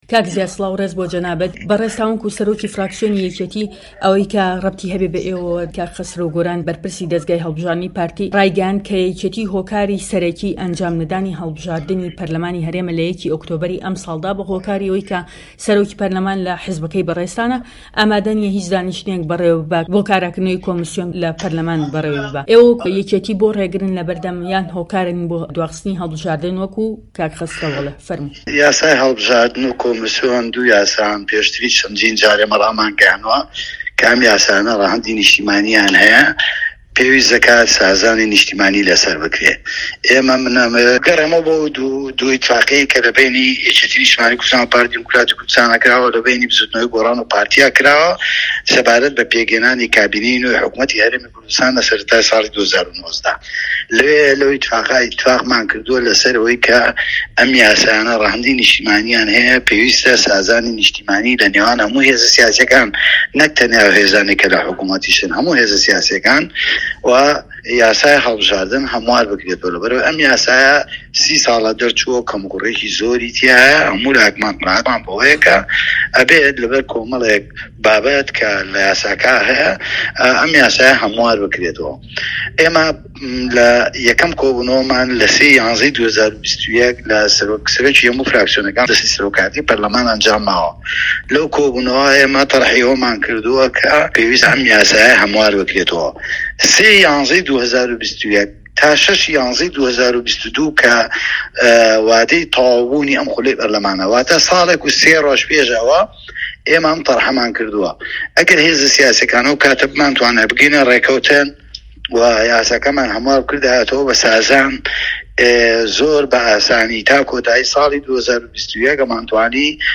دەقی وتووێژەکەی زیاد جەبار